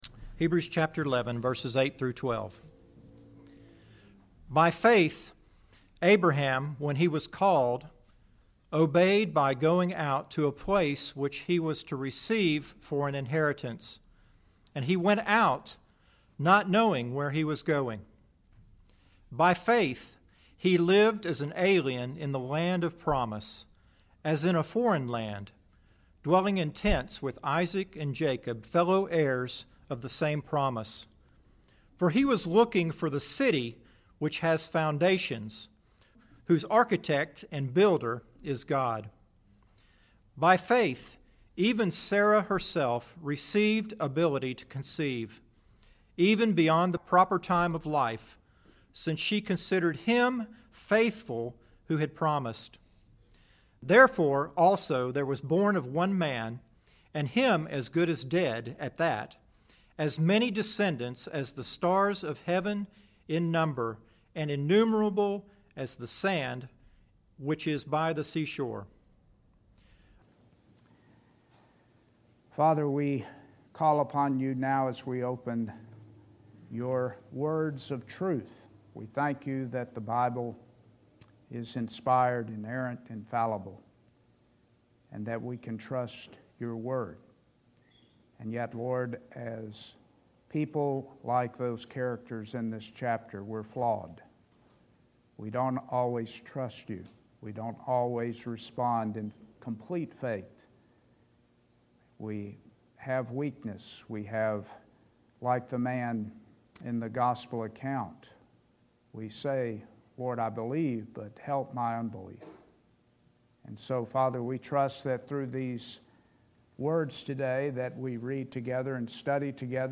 McCalla Bible Church Sermons